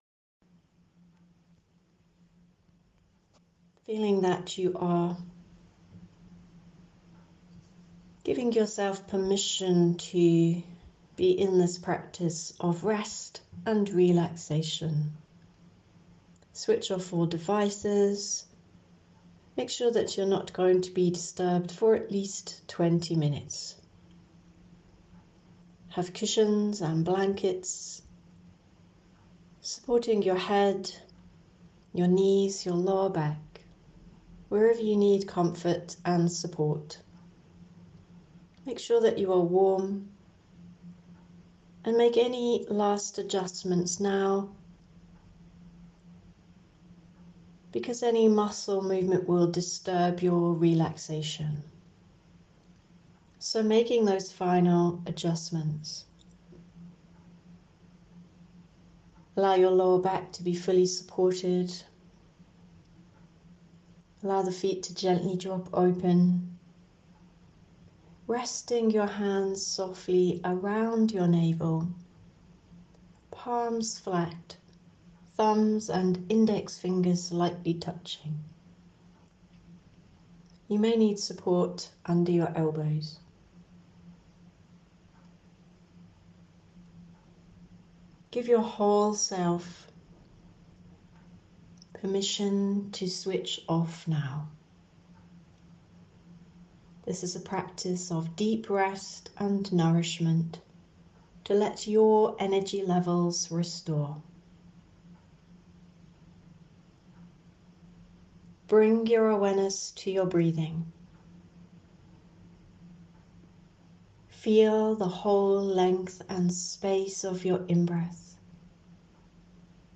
Deep-Relaxation-15-mins.m4a